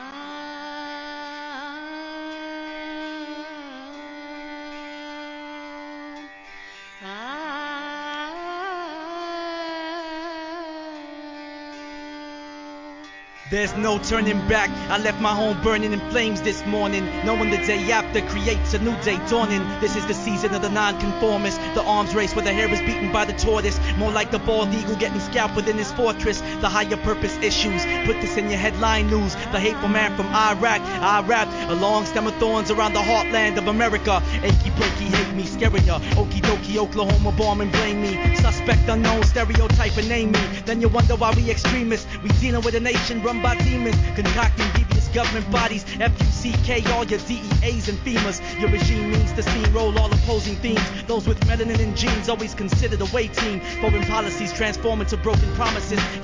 HIP HOP/R&B
エスニックなプロダクション!